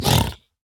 Minecraft Version Minecraft Version snapshot Latest Release | Latest Snapshot snapshot / assets / minecraft / sounds / mob / piglin_brute / hurt3.ogg Compare With Compare With Latest Release | Latest Snapshot
hurt3.ogg